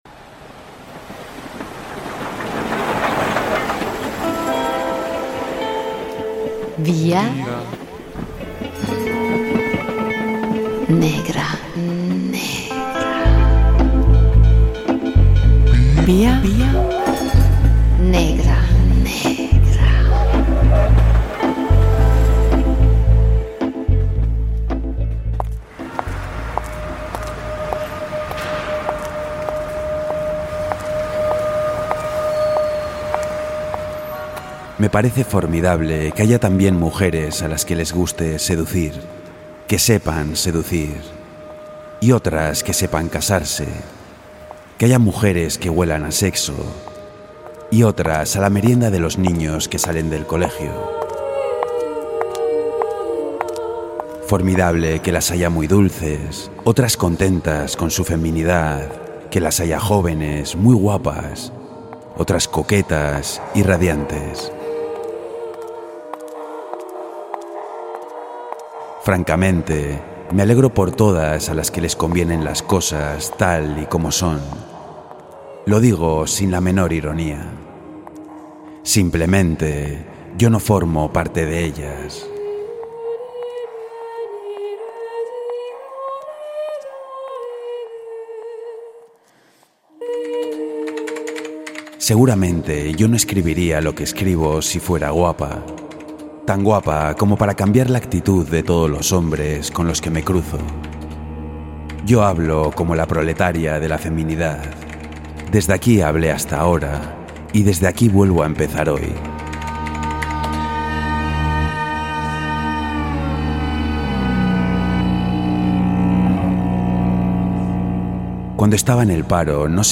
"Sólo rabia" novela negra sonorizada en Boulevard Magazine